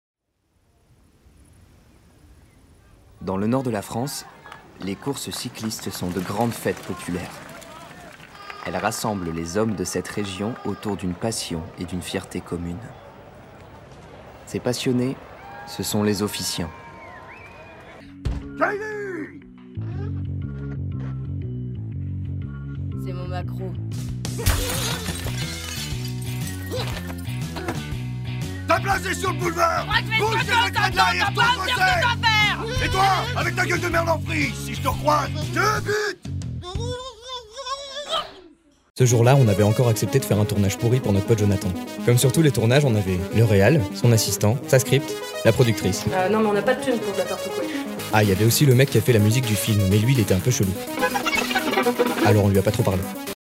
Voix off
Démo voix 1